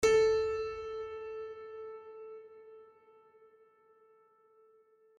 piano-sounds-dev
HardPiano